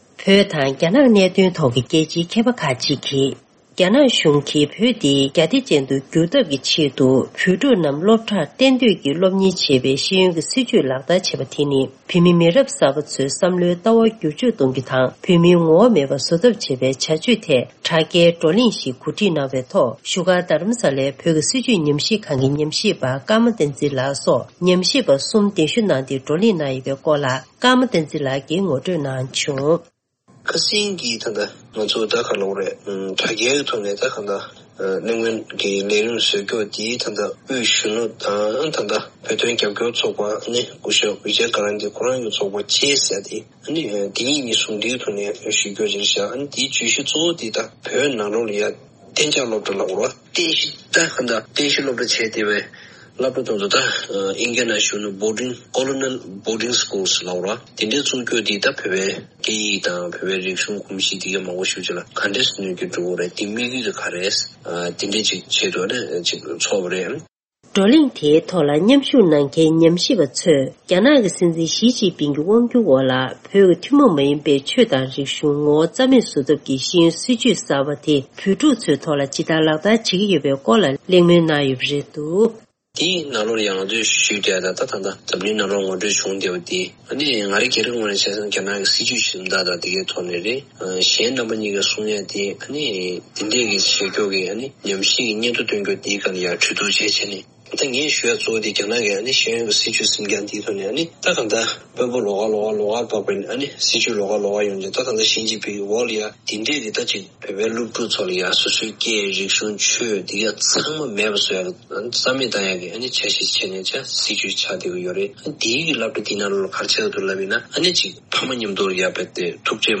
སྒྲ་ལྡན་གསར་འགྱུར། སྒྲ་ཕབ་ལེན།
ཐེངས་འདིའི་གསར་འགྱུར་དཔྱད་གཏམ་གྱི་ལེ་ཚན་ནང་།